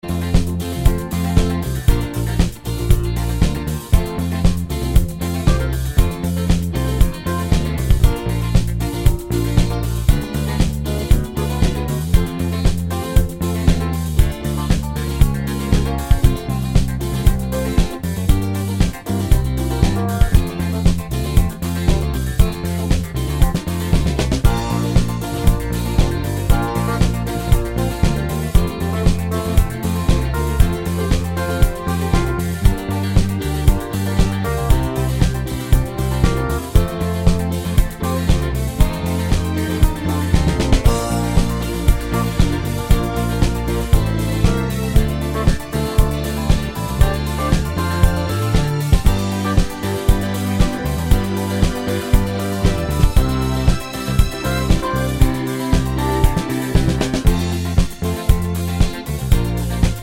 no Backing Vocals Crooners 2:27 Buy £1.50